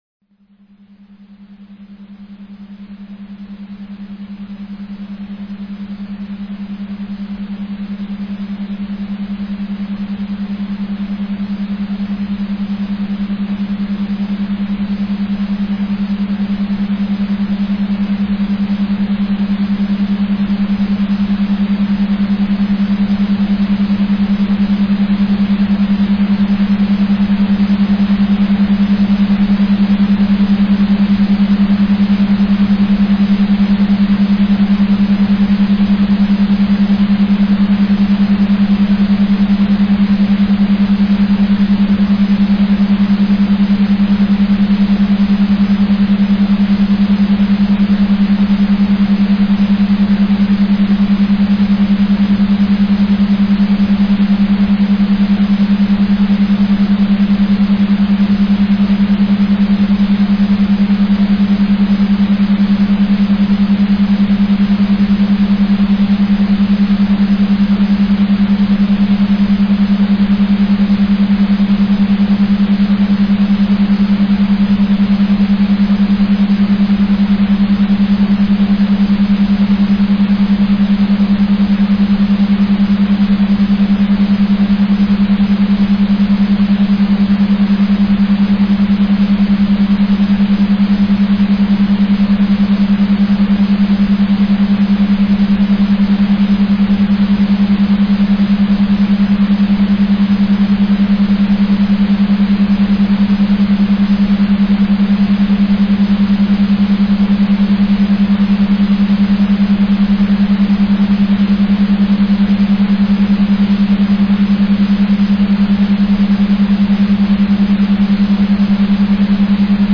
Цифровой наркотик (аудио наркотик) На краю пропасти